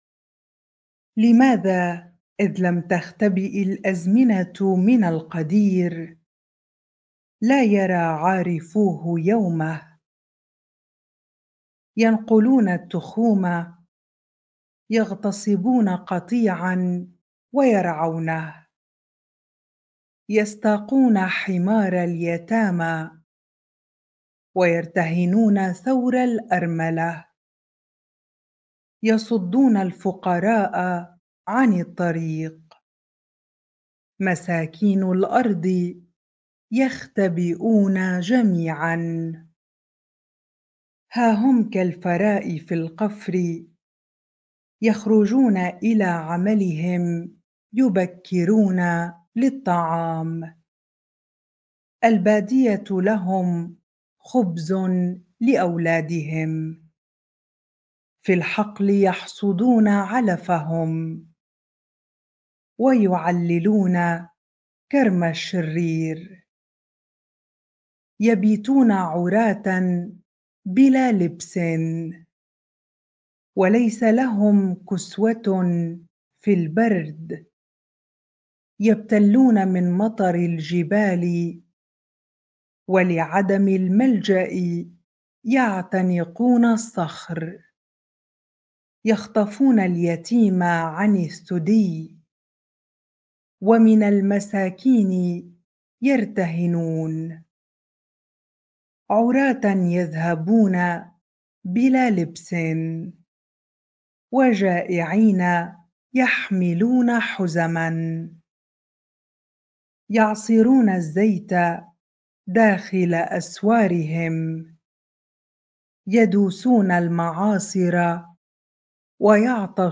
bible-reading-Job 24 ar